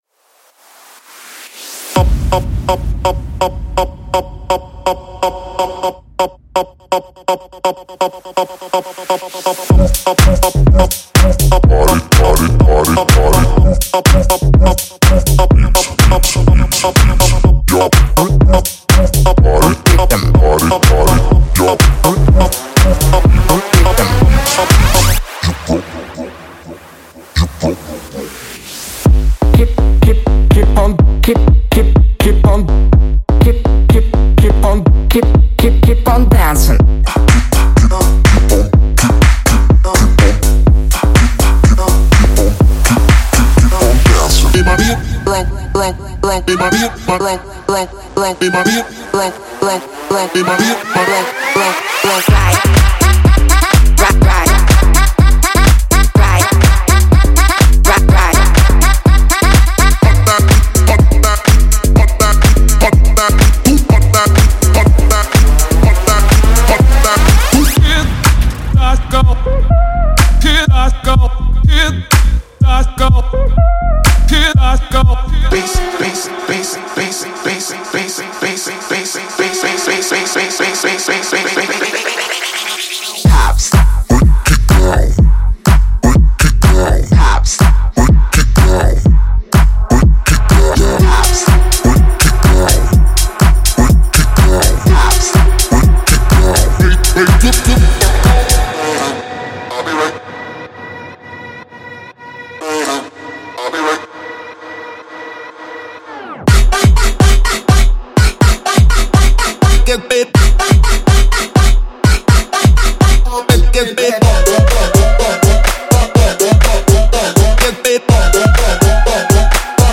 这只是一个人声包。仅包括演示中的人声。演示中的其他声音仅用于说明目的。
.124BPM
.85个旋律声乐循环（干湿版本)
.115个声乐回合循环（干湿版本)